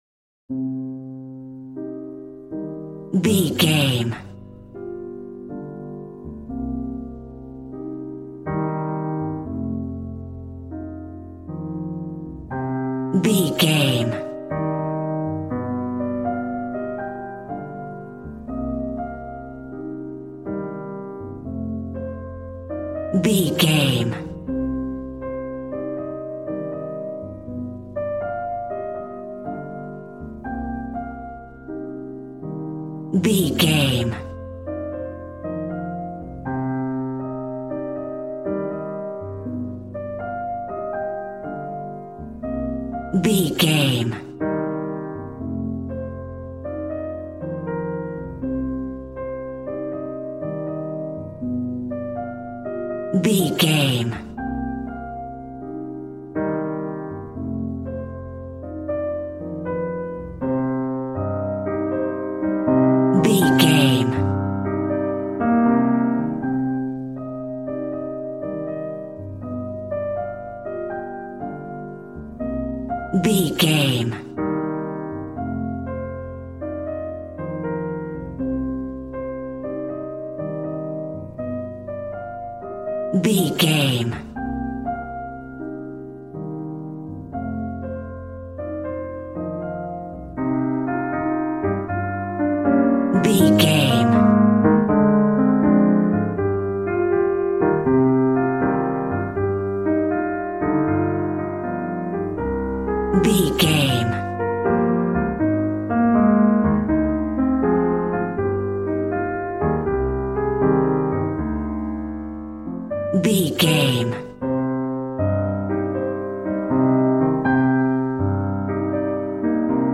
Smooth jazz piano mixed with jazz bass and cool jazz drums.,
Aeolian/Minor
drums